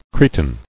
[cre·tin]